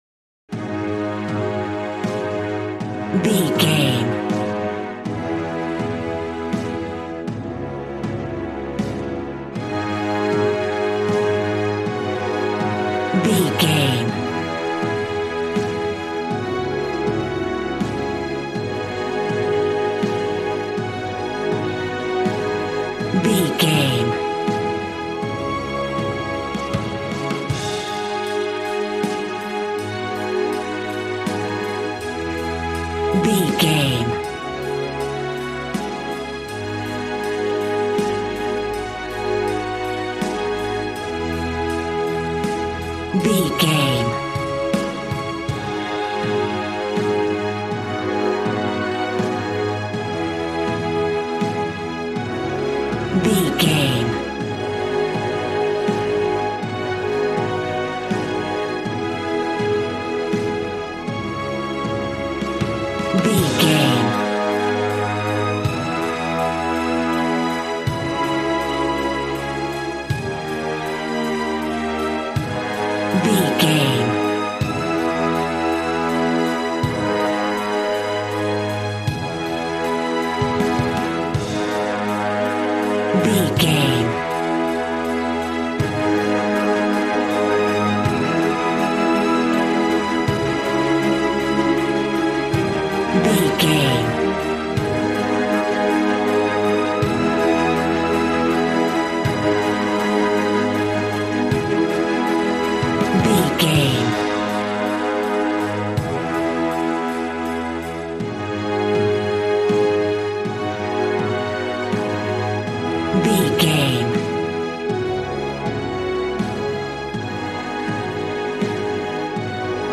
Aeolian/Minor
A♭
strings
violin
brass